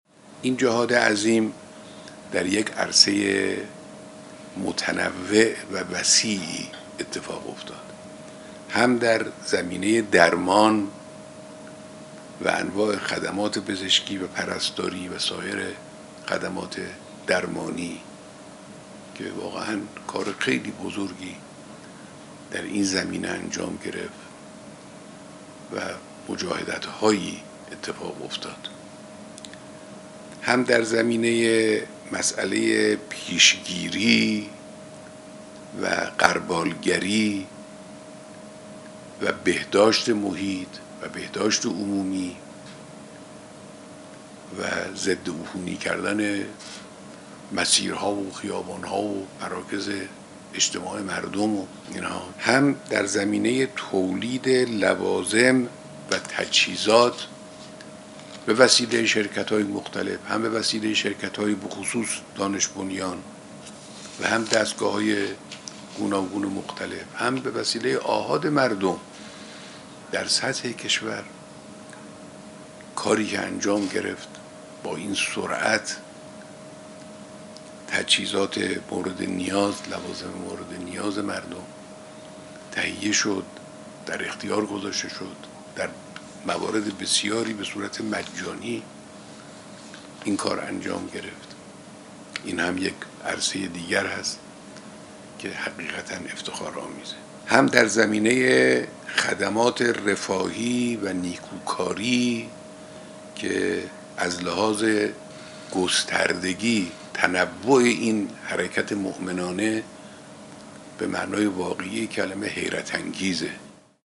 رهبر انقلاب در ارتباط تصویری ستاد ملی مبارزه با کرونا: جهاد عظیم و متنوع مردم در مقابله با کرونا حقیقتا افتخارآمیز و حیرت‌انگیز است.